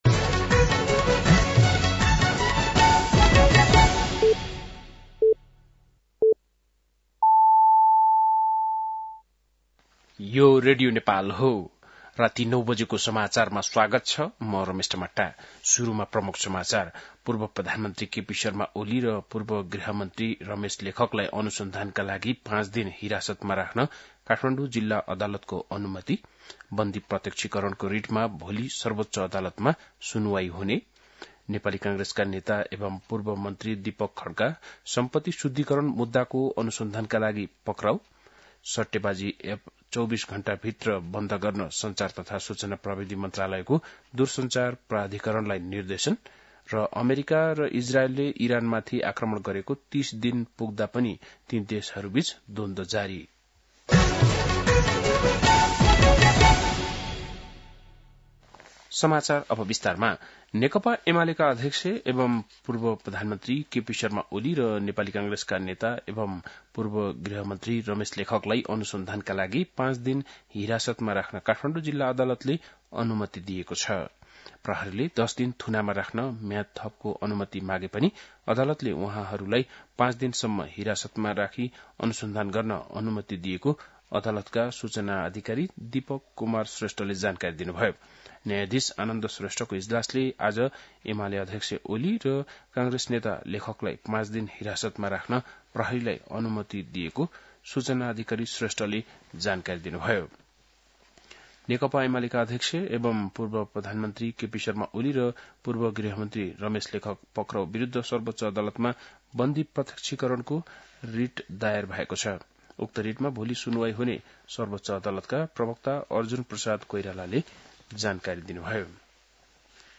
बेलुकी ९ बजेको नेपाली समाचार : १५ चैत , २०८२
9-pm-english-news-.mp3